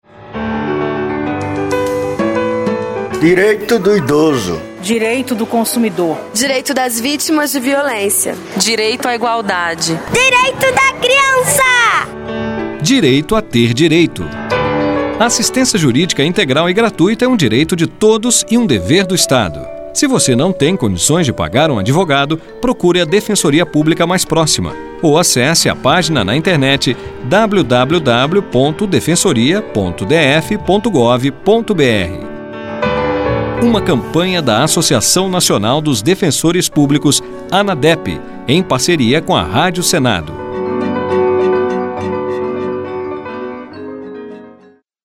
SPOTS: